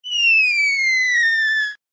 MG_cannon_whizz.ogg